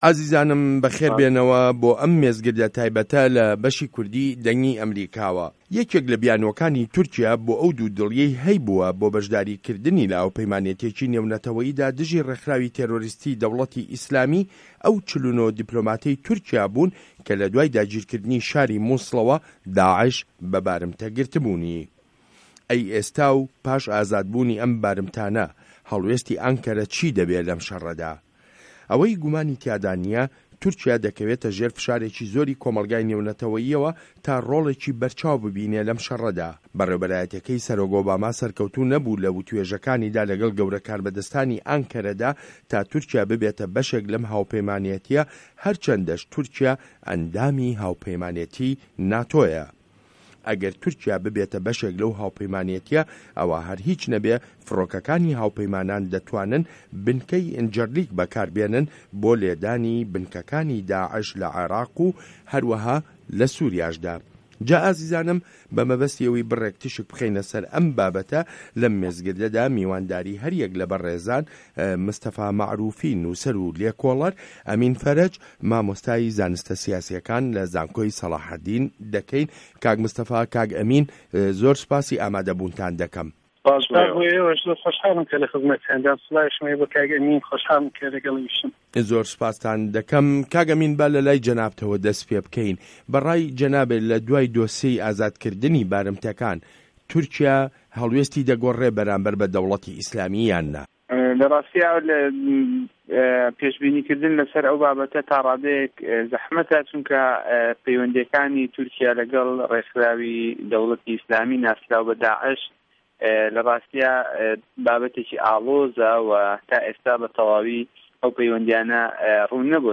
مێزگرد: تورکیا بیانوی بارمته‌کانیشی نه‌ما